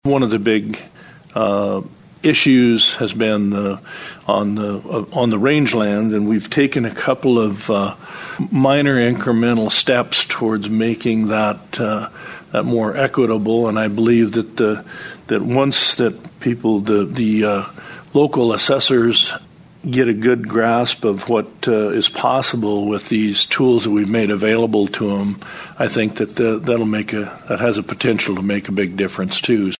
Cammack says they have made some recommendations on grassland valuations.